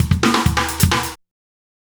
drums short01.wav